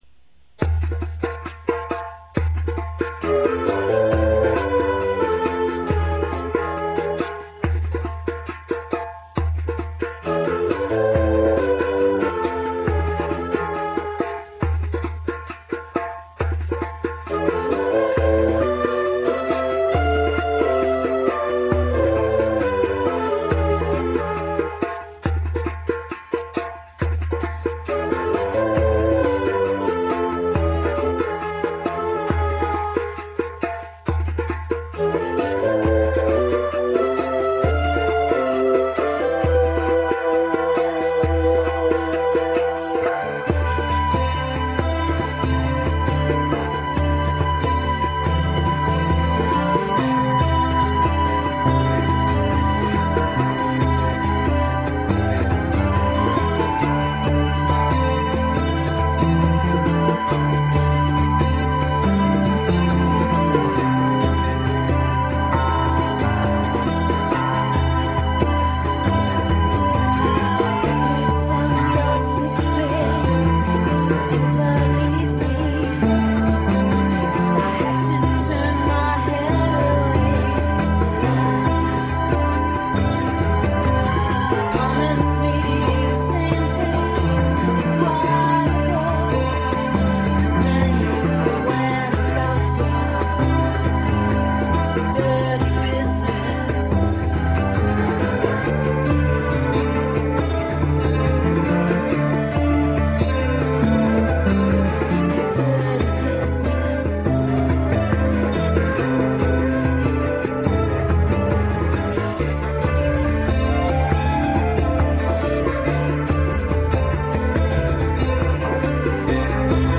World
There are no guitars to be found.